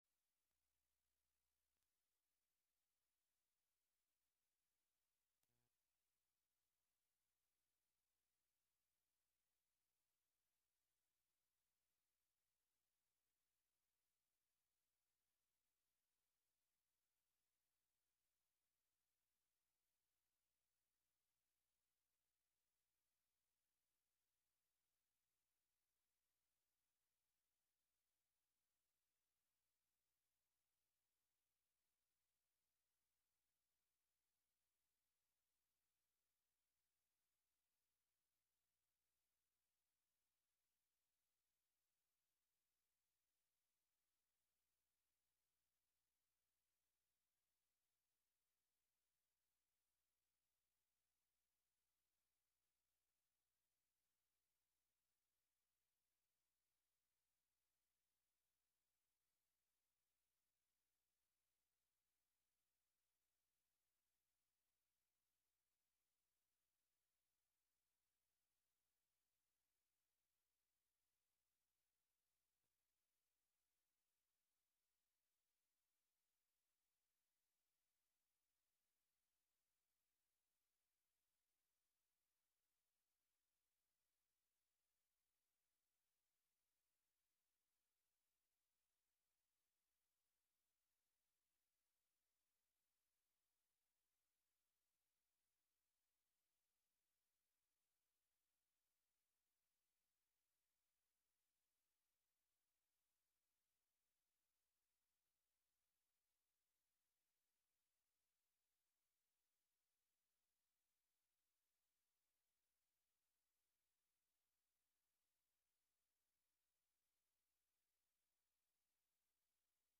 Prime Jive: Monday Afternoon Show- Live from Housatonic, MA (Audio)
broadcasts live with music, call-ins, news, announcements, and interviews